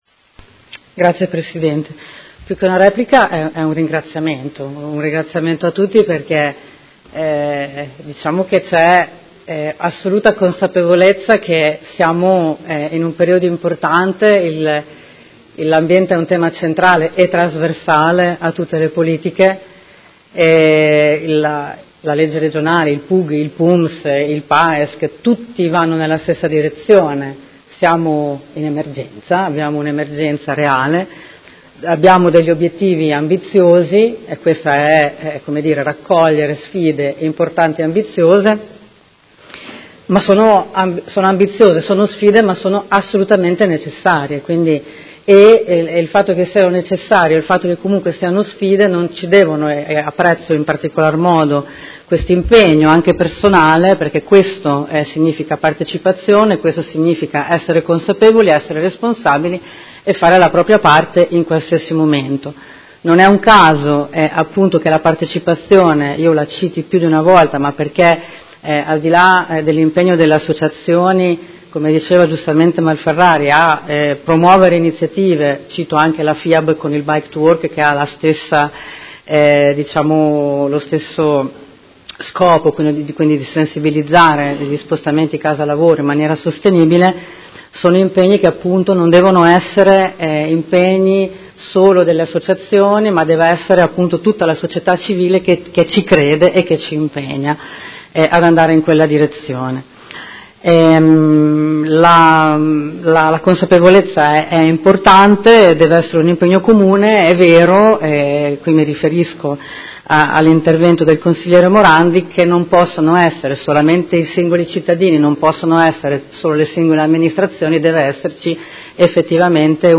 Alessandra Filippi — Sito Audio Consiglio Comunale
Seduta del 07/02/2019 Replica a dibattito.